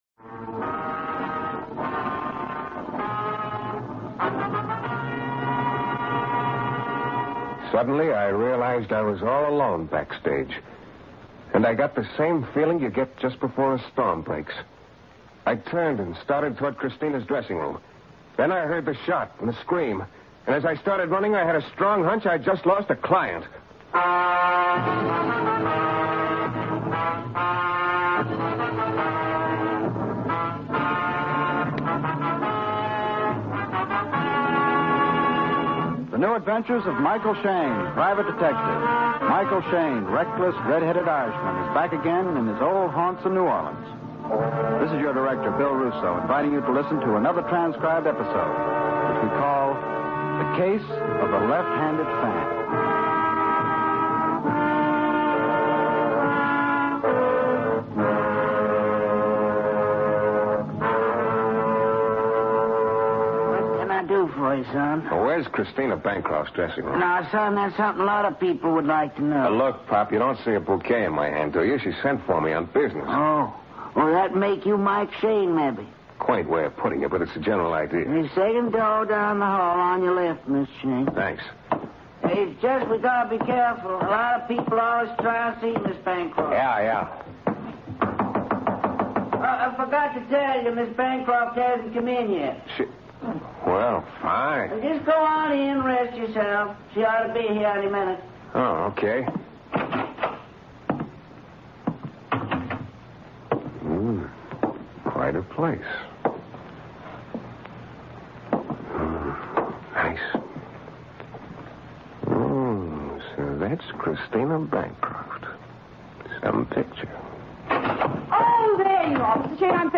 Michael Shayne 460521 The Case of the Left Handed Fan, Old Time Radio